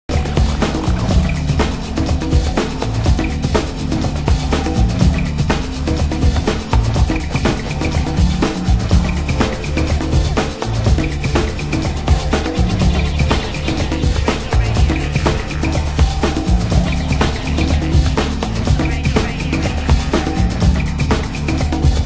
盤質：良好/A1の中盤に小さなプレスノイズ